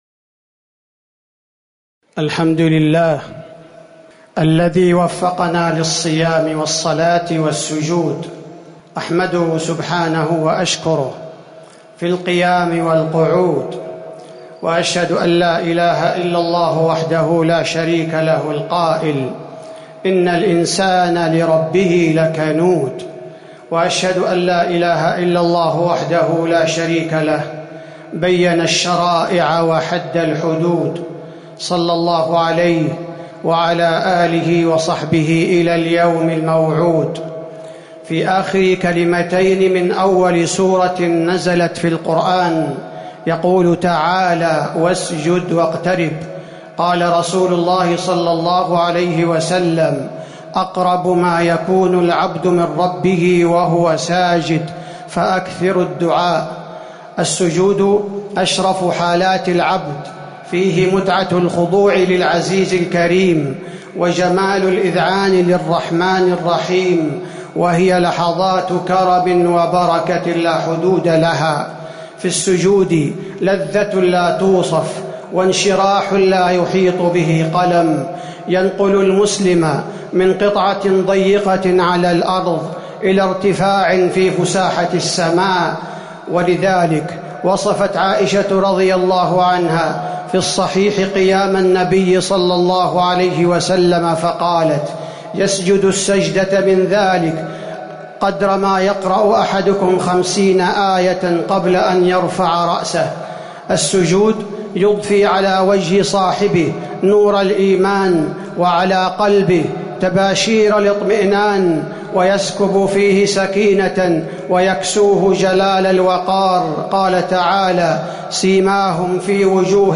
تاريخ النشر ١٢ رمضان ١٤٤٠ هـ المكان: المسجد النبوي الشيخ: فضيلة الشيخ عبدالباري الثبيتي فضيلة الشيخ عبدالباري الثبيتي واسجد واقترب